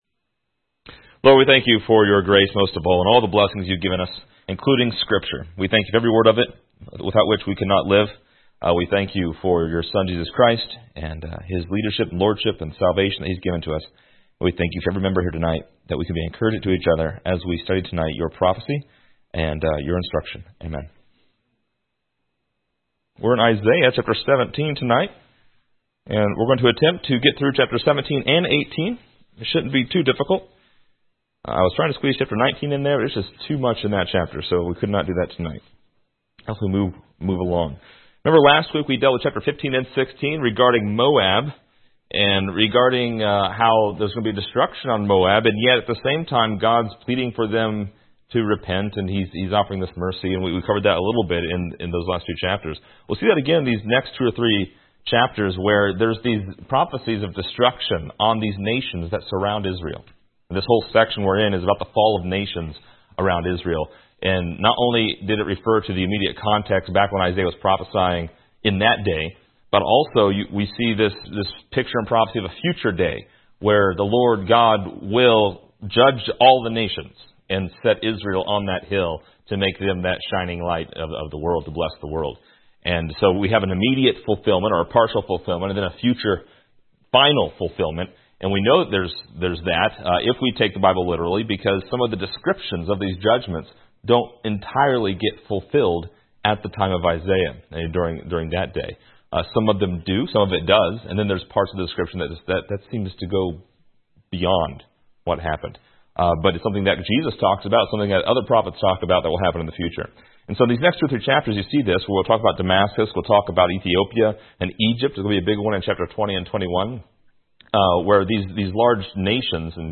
Description: This lesson is part 24 in a verse by verse study through Isaiah titled: Damascus Ruined.